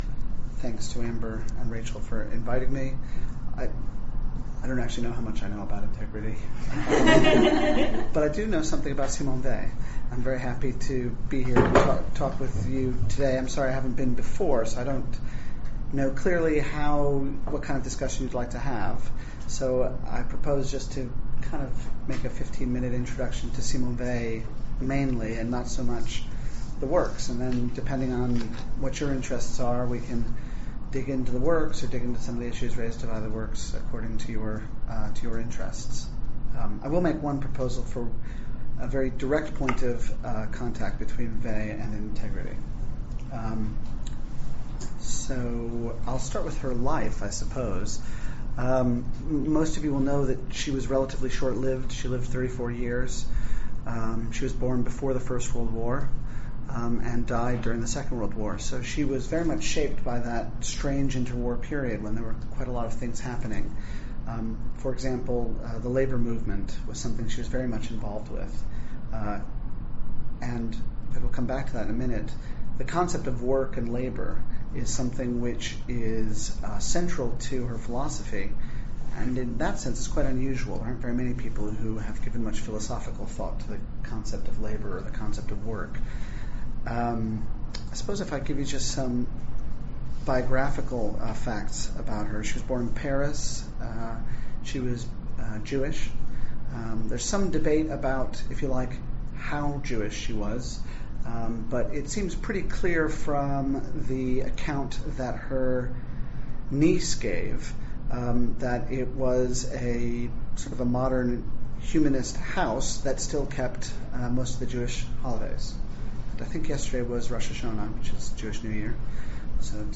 Introductory lecture (mp3 file)